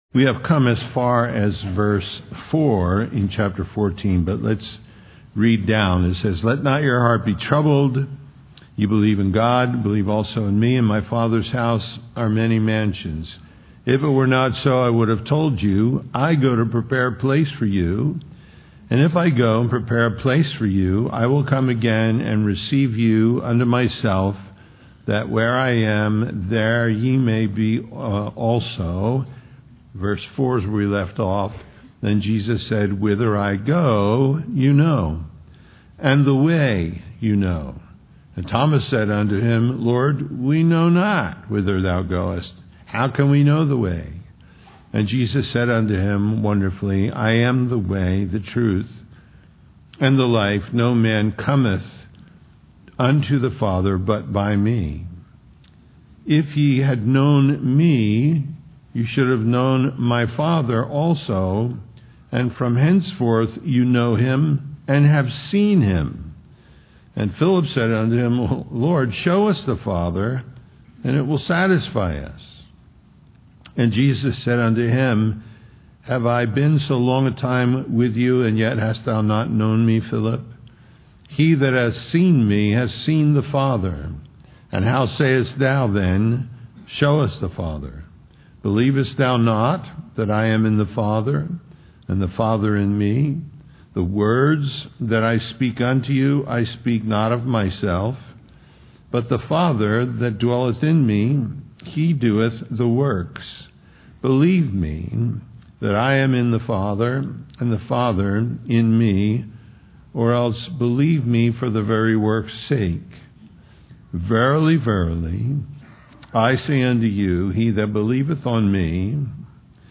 John 14:4-14:14 The Way Home Listen Download Original Teaching Email Feedback 14 And whither I go ye know, and the way ye know.